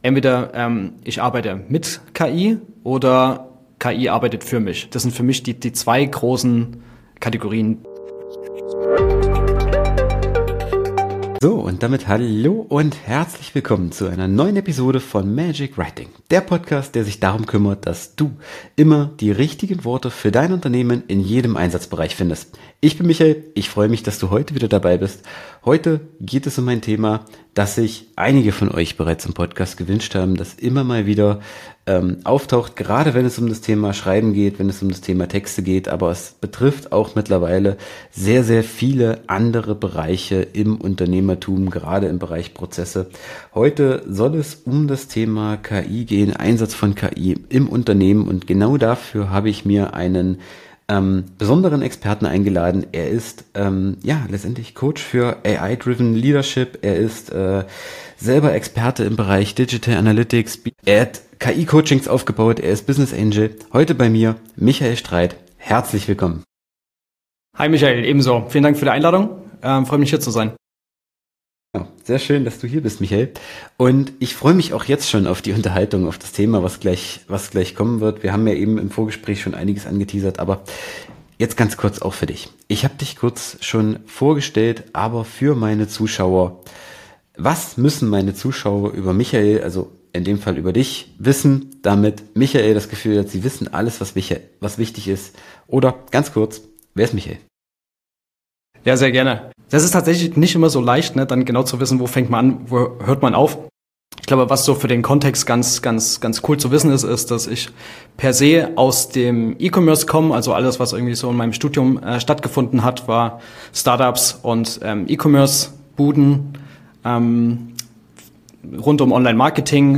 Folge 289: Einsatz von Künstlicher Intelligenz im Unternehmen - Interview